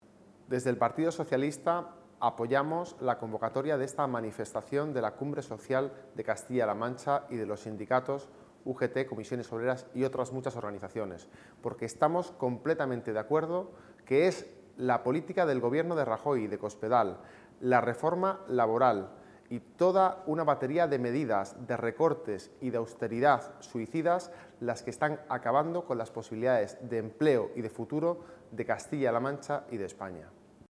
El secretario general del PSOE de Guadalajara y alcalde de Azuqueca de Henares, Pablo Bellido, ha valorado hoy, en nombre del PSOE regional, las marchas de protestas convocadas en las cinco capitales de provincia de Castilla-La Mancha por la Cumbre Social y los sindicatos CCOO y UGT.
Cortes de audio de la rueda de prensa